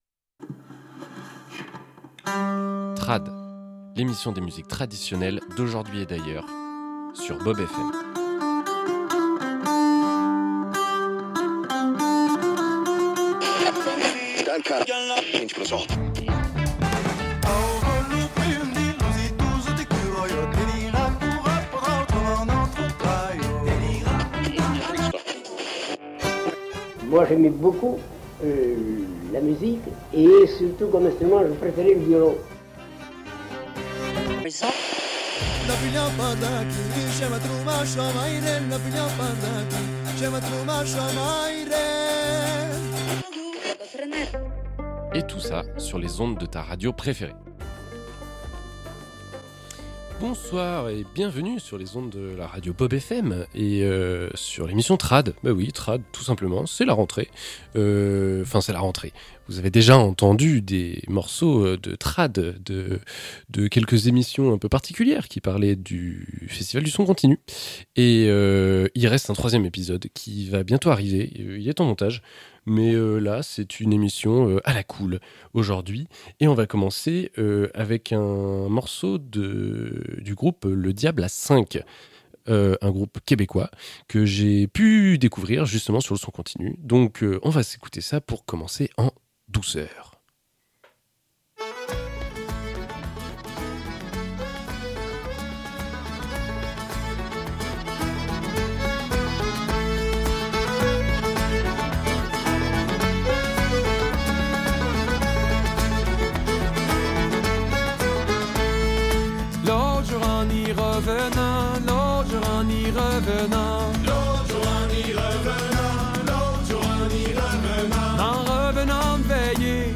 Pour cette première émission de rentrée dans les studios de la radio BeaubFm, Trad’ l’émission va dans tous les sens ! Électro trad marocain, valses auvergnates, groove québécois et autres musiques répétitives sur fond de tradition revisité seront à l’honneur dans le sixième épisode de Trad !